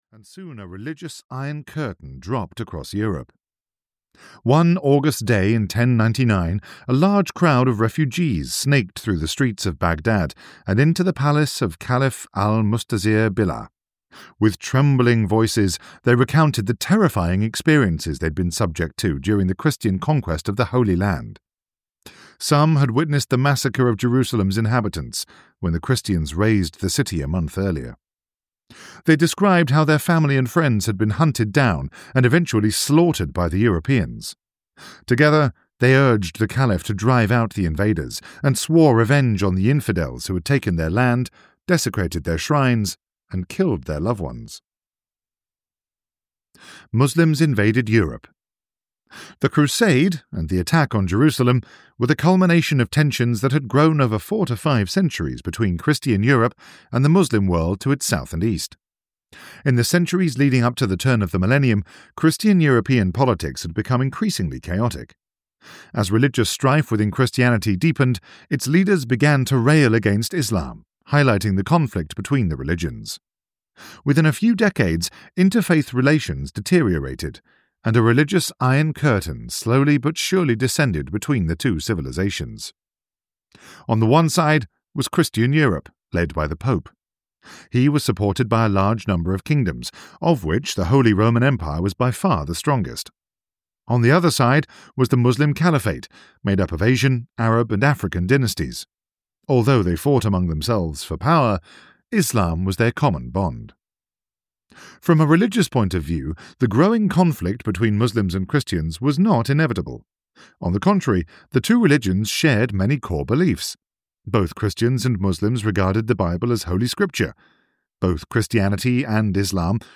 The Crusades (EN) audiokniha
Ukázka z knihy